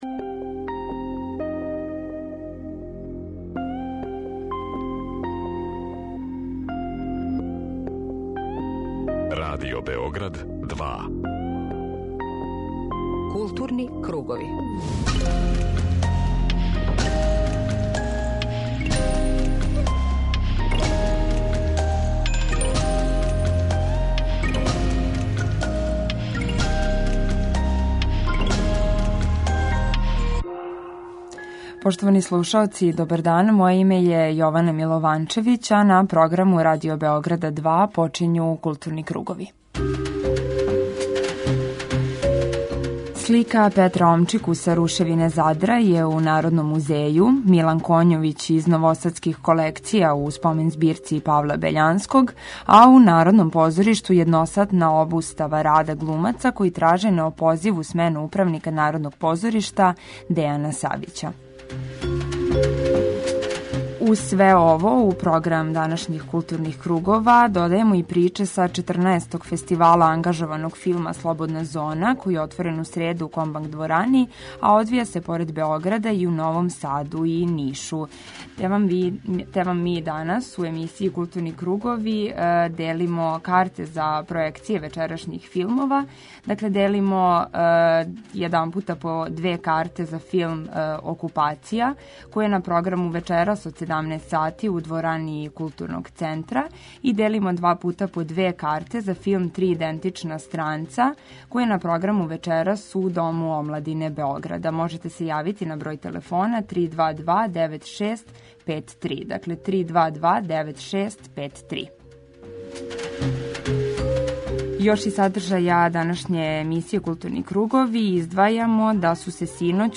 Dnevni magazin kulture Radio Beograda 2